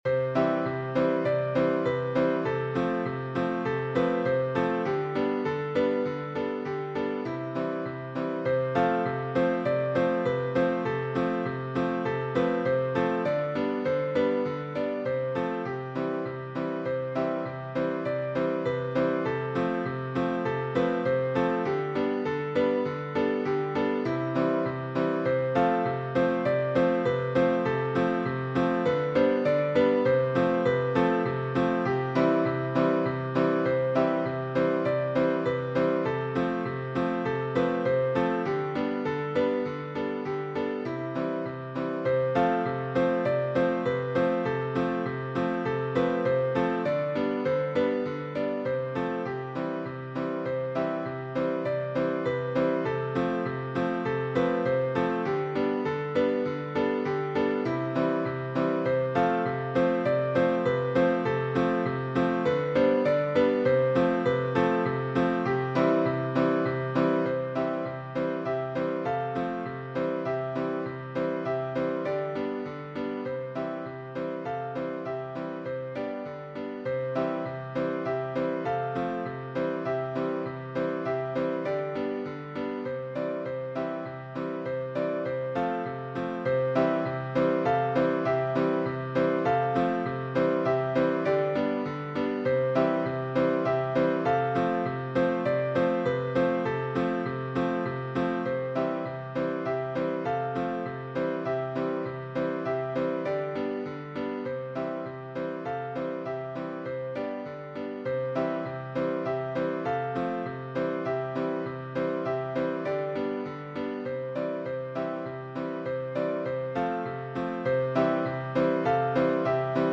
Joyous
Modern ragtime
Piano only